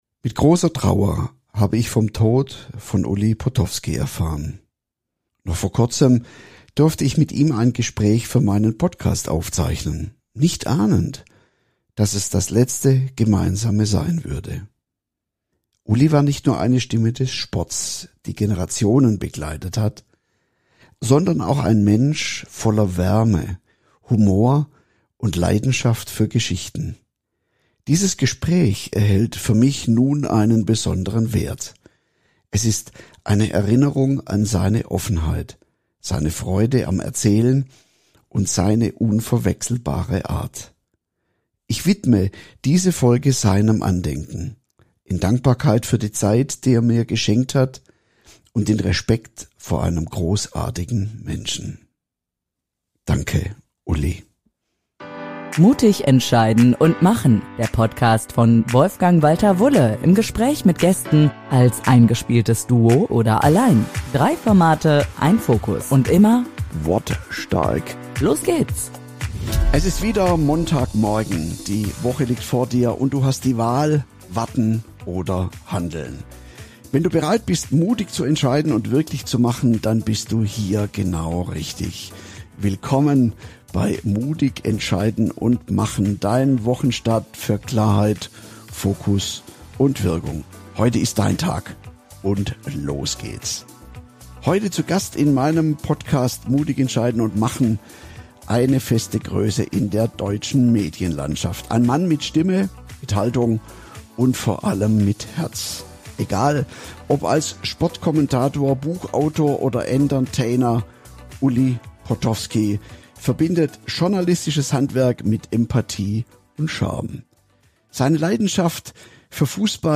Noch vor kurzem durfte ich mit ihm ein Gespräch für meinen Podcast aufzeichnen - nicht ahnend, dass es das letzte Gemeinsame sein würde.